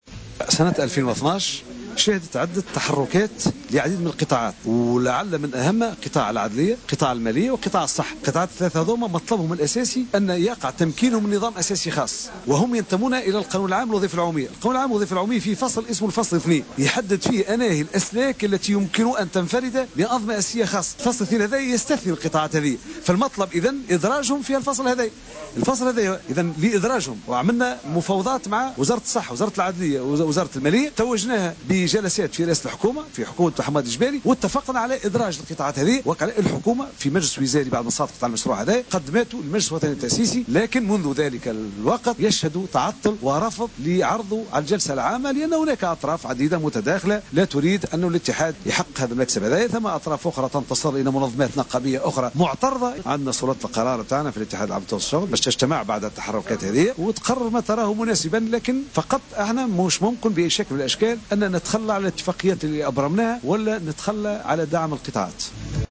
خلال ندوة صحفية اليوم الثلاثاء